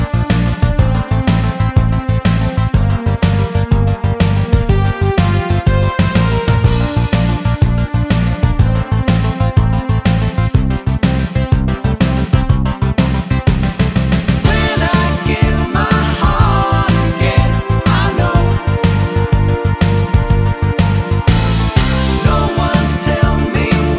No Bass Pop (1970s) 4:47 Buy £1.50